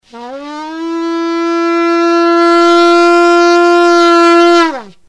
We do know that it was to be a holy day celebrated with trumpet blasts of the shofar (a trumpet made from a ram's horn).
During the blowing of the shofar on Rosh Hashana there are three distinct sounds.
Tekiah.mp3